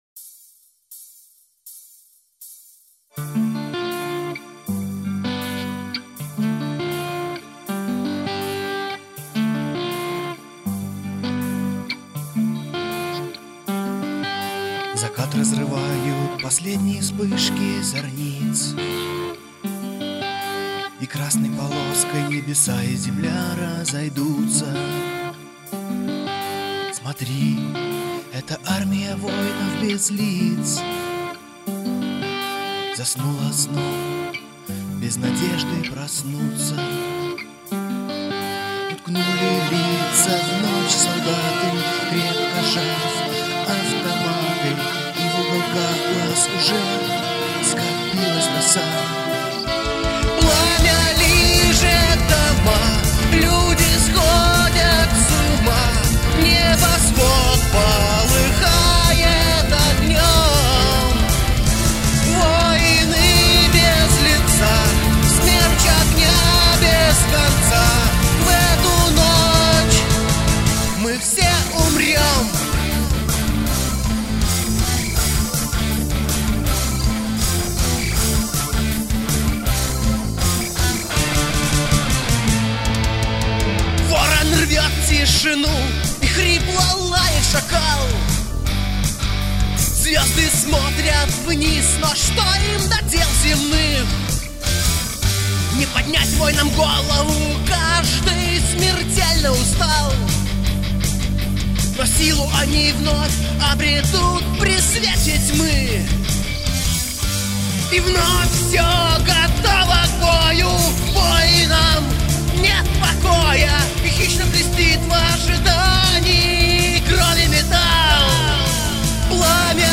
Гитары, бас, клавиши, перкуссия, вокал